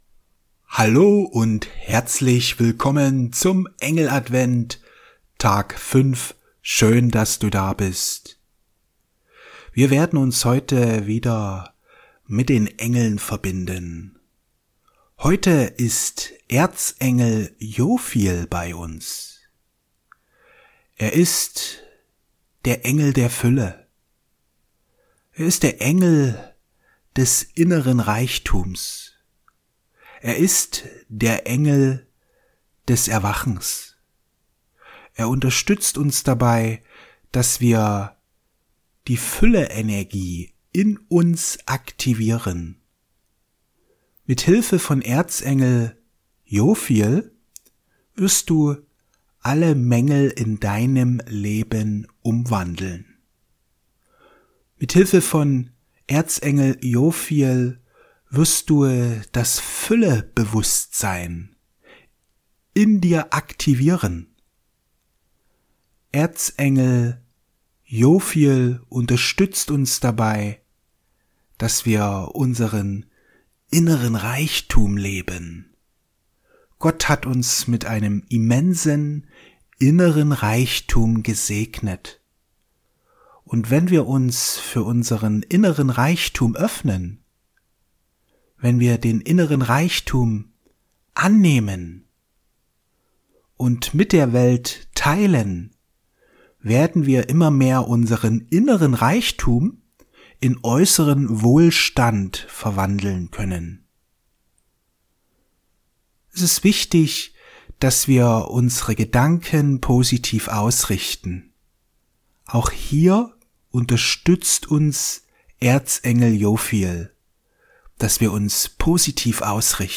Die Aktivierung deines Fülle-Bewusstseins Meditation mit Erzengel Jophiel